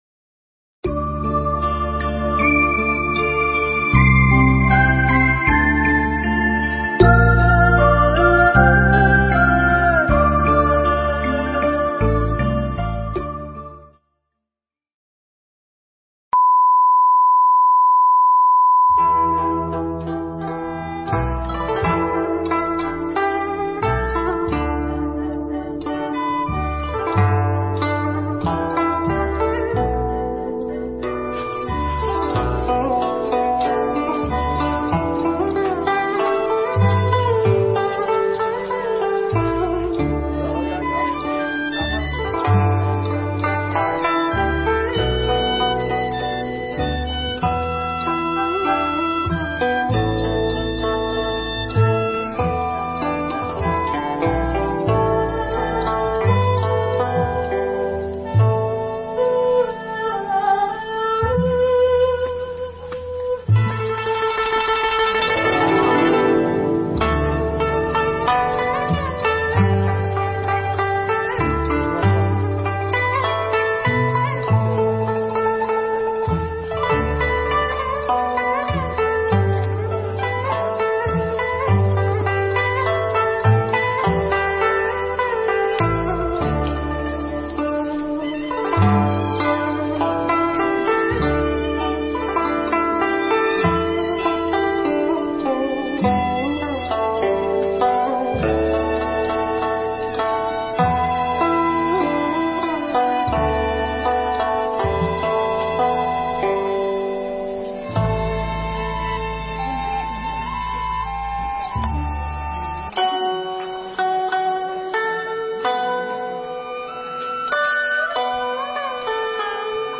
Pháp âm
giảng nhân ngày Quốc Tế Phụ Nữ tại Cơm Chay Bồ Đề Tâm (Hà Nội)